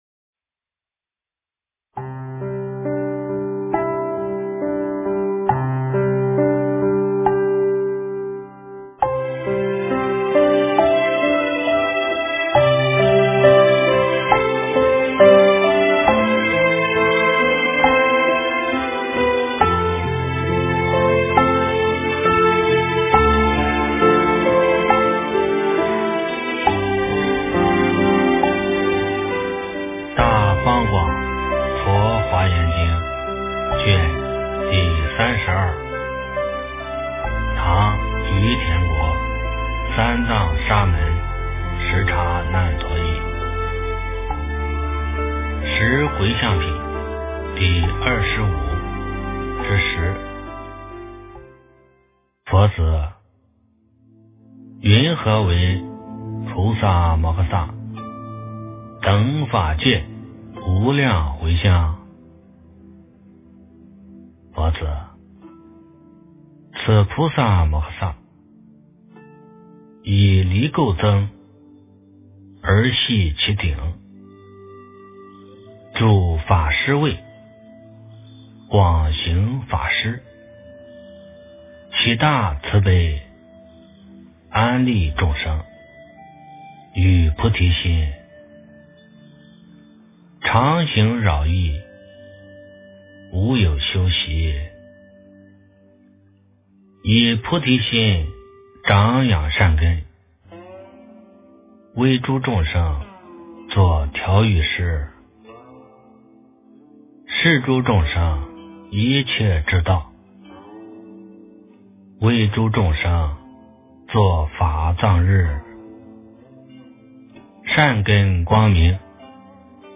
《华严经》32卷 - 诵经 - 云佛论坛